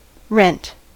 rent: Wikimedia Commons US English Pronunciations
En-us-rent.WAV